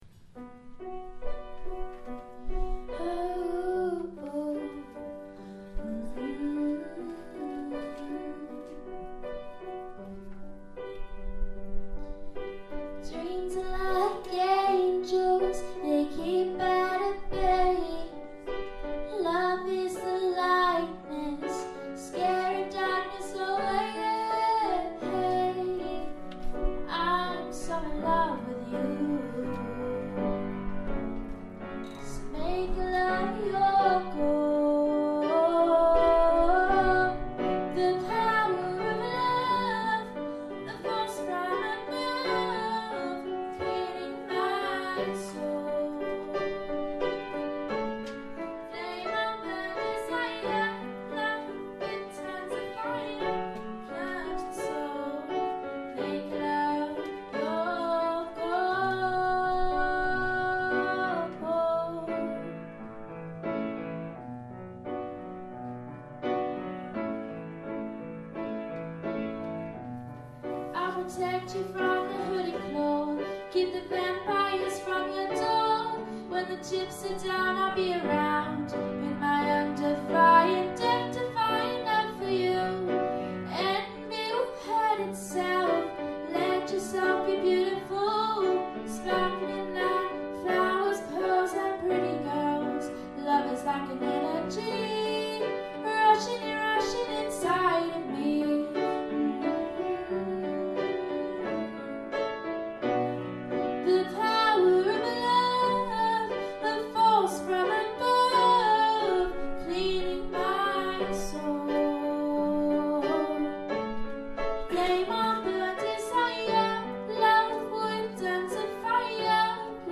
The Power of Love - Pop singing evening